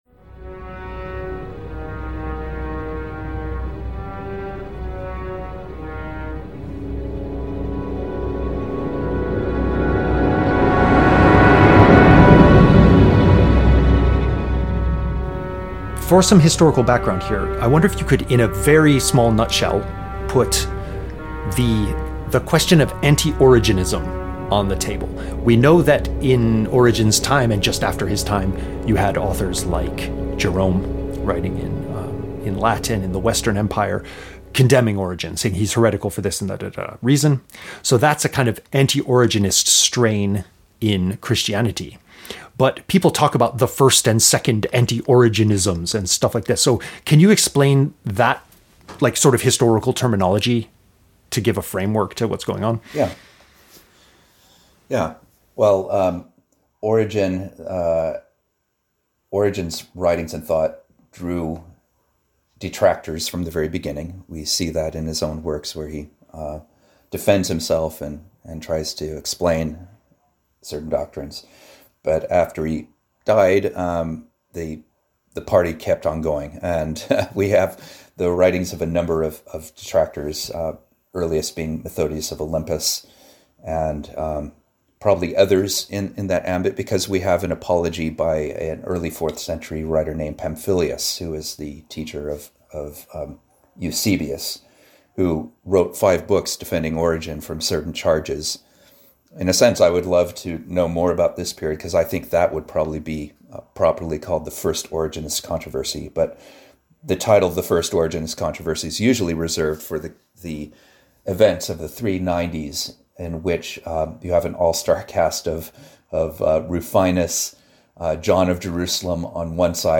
Interview Bio